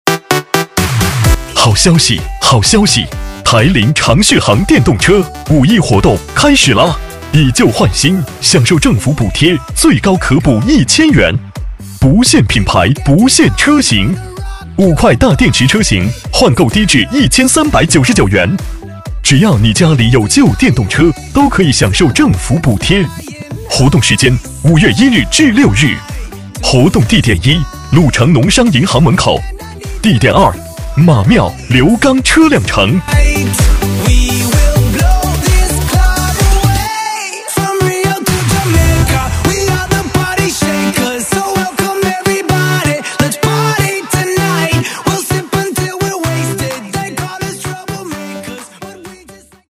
【男60号促销】台铃.mp3